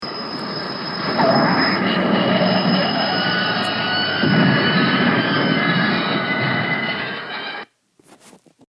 freinageurgence.mp3